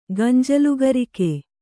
♪ gañjalu garike